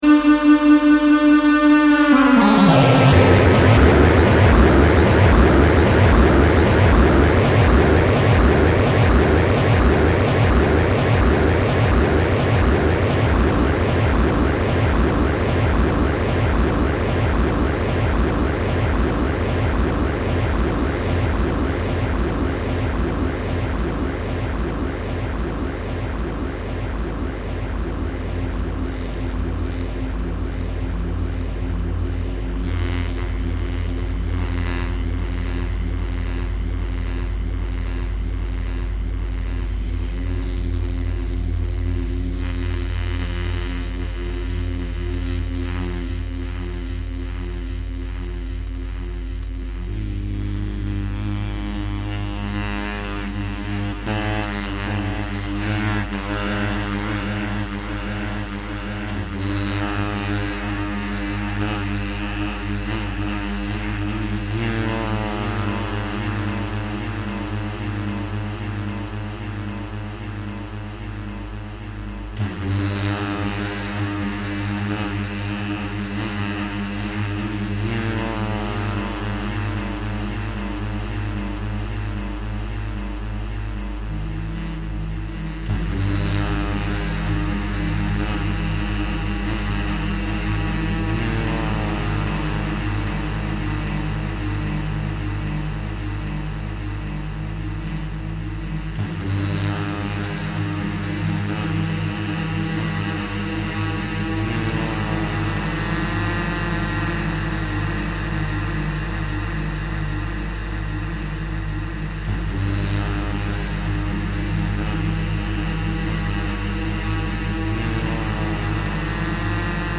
new way to generate tones
2 tracks and a short loop